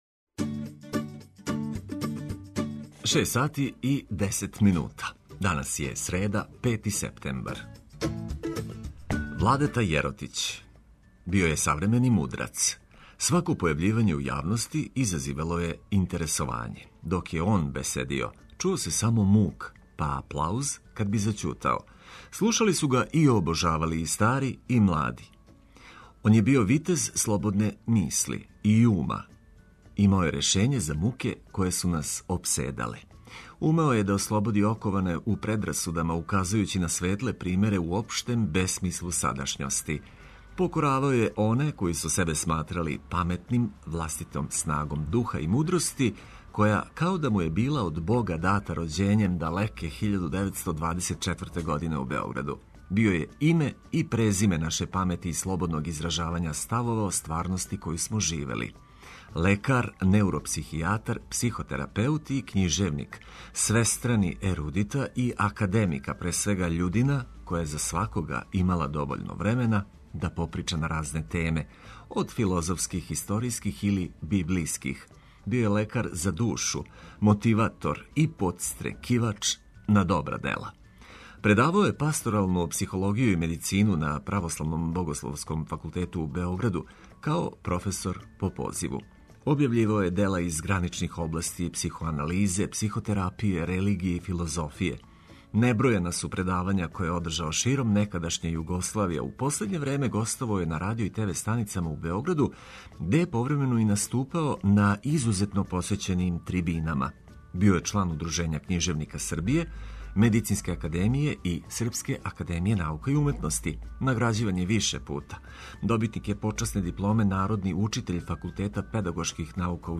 У нови дан закорачићемо уз сјајну музику и корисне вести.